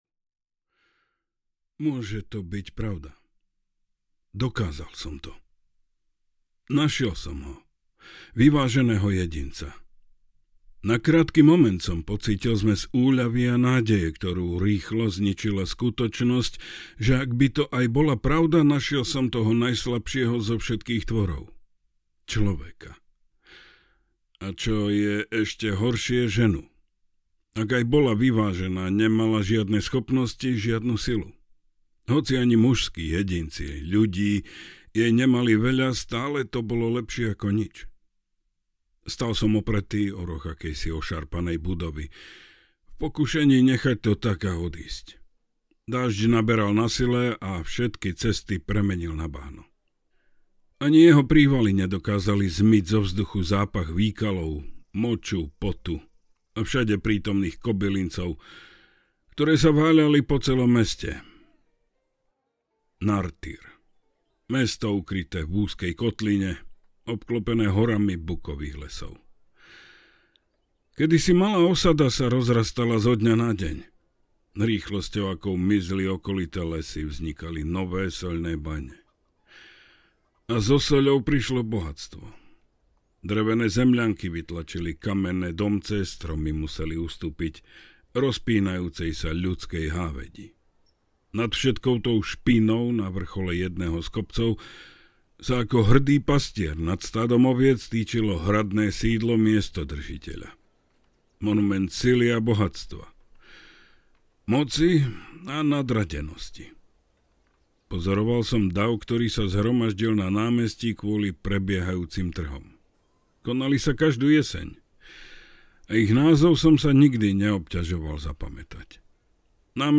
Slepý život – Večná lož audiokniha
Ukázka z knihy